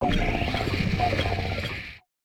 Cri de Mite-de-Fer dans Pokémon Écarlate et Violet.